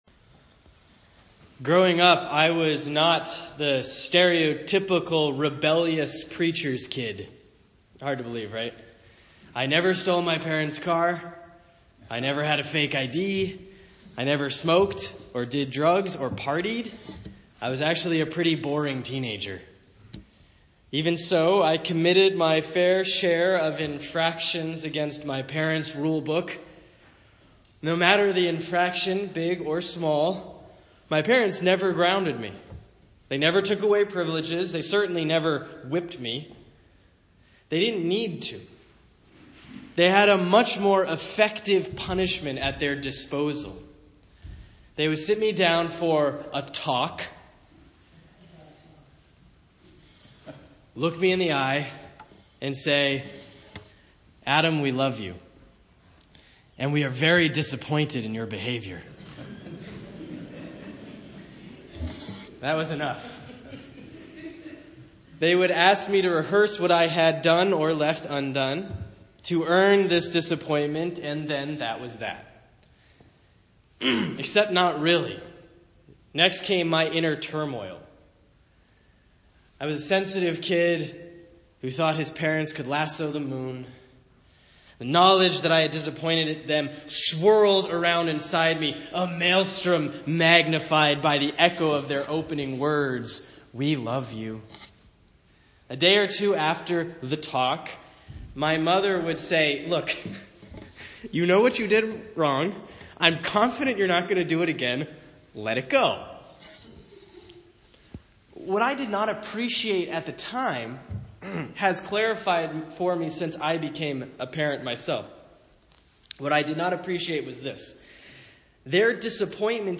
A sermon about the two types of justice, especially about the better kind which gets less press.